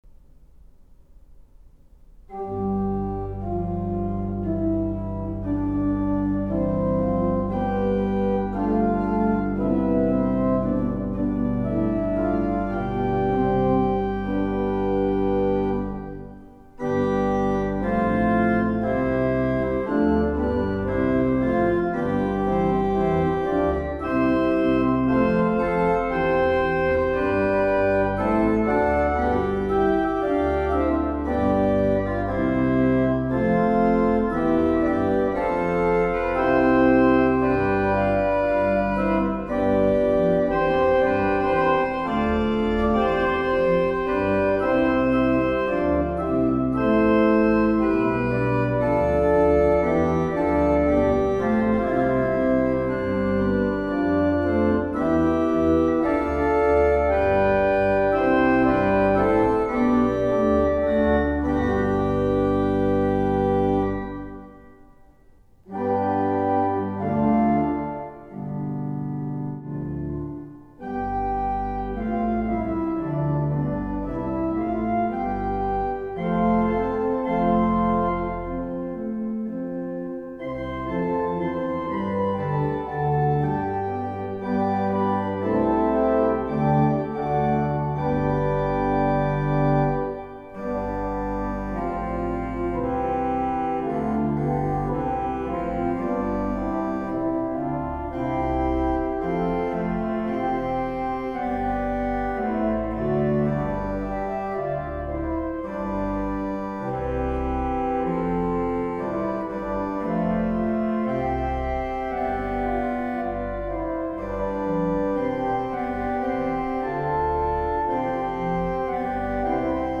Partituren en opnamen van de psalmen Tenzij anders vermeld zijn de opnamen gemaakt in 2018 - 2022 met een Tascom DR-05 Linear PCM-recorder.